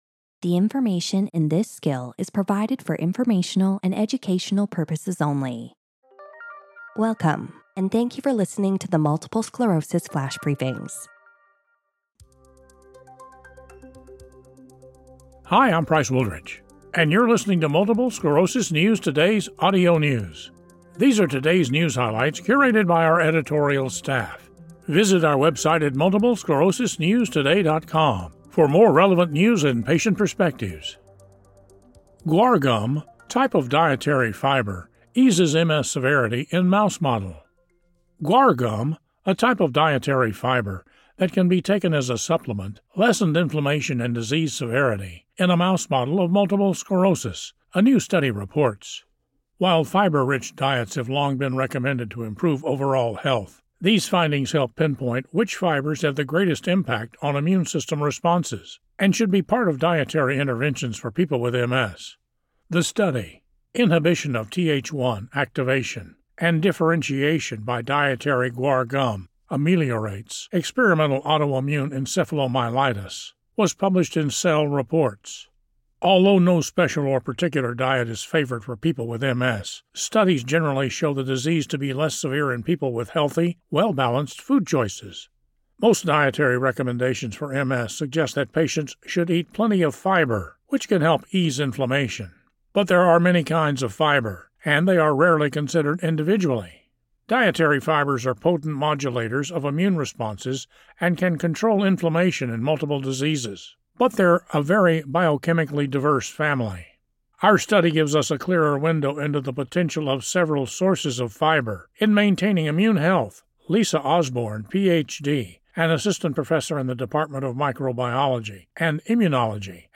reads a news article reporting on how guar gum, a type of dietary fiber that can be taken as a supplement, lessened inflammation and disease severity in a mouse model of MS.